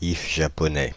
Ääntäminen
Synonyymit if du Japon Ääntäminen France (Île-de-France): IPA: /if ja.pɔ.nɛ/ Haettu sana löytyi näillä lähdekielillä: ranska Käännöksiä ei löytynyt valitulle kohdekielelle.